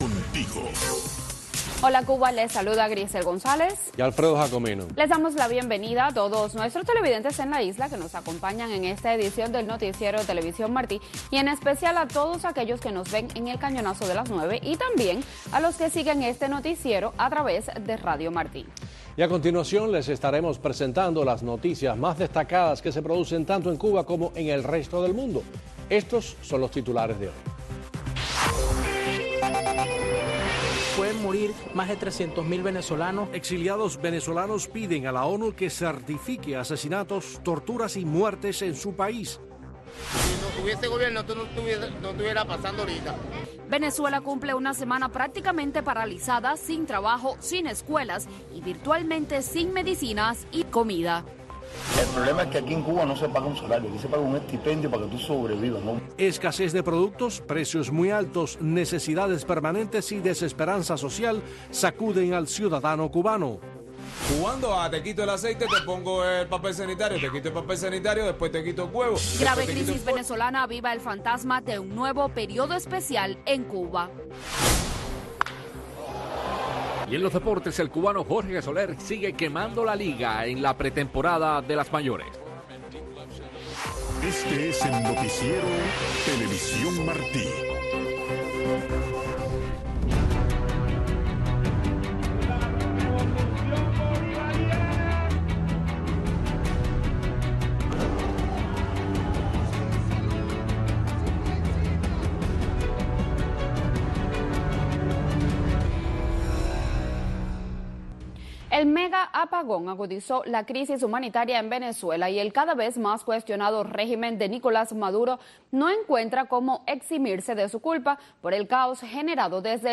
Noticiero de TV Martí